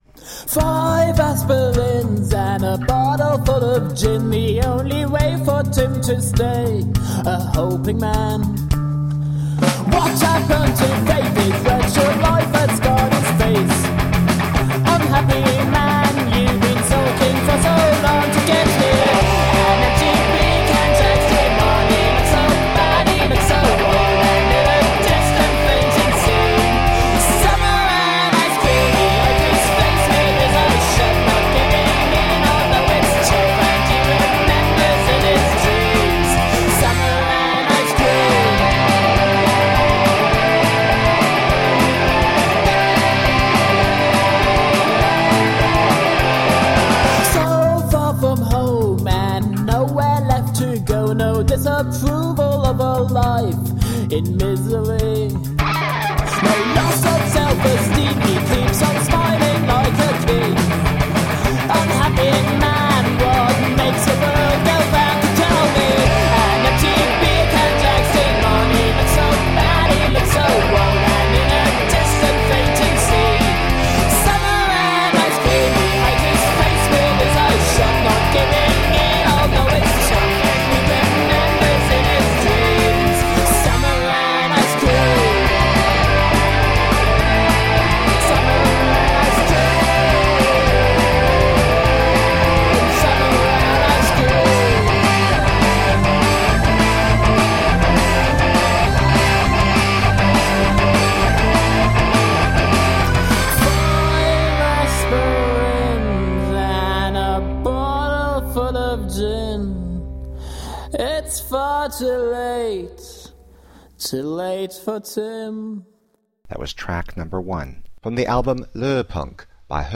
Noisy, friendly, fierce and beautiful, rock and roll.
Tagged as: Alt Rock, Rock